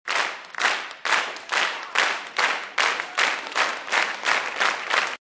rhythmic_clapping